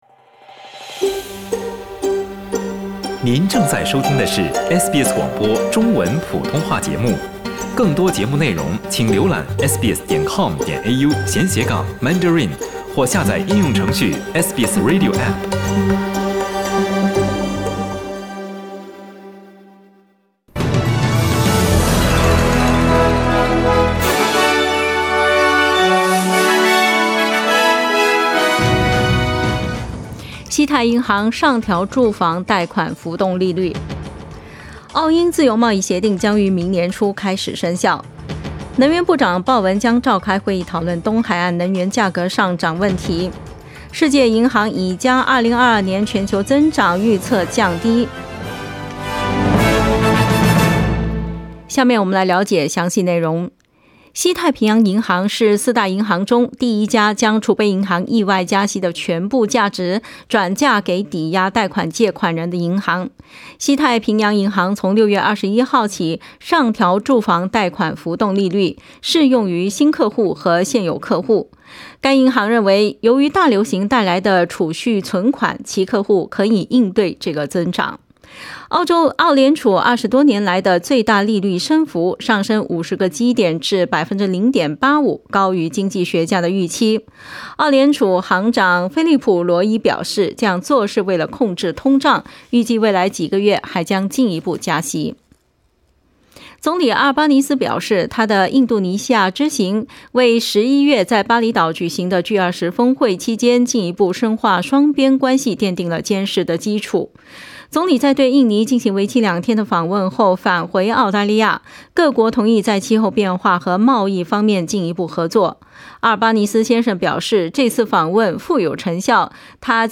SBS早新聞（6月8日）
請點擊收聽SBS普通話為您帶來的最新新聞內容。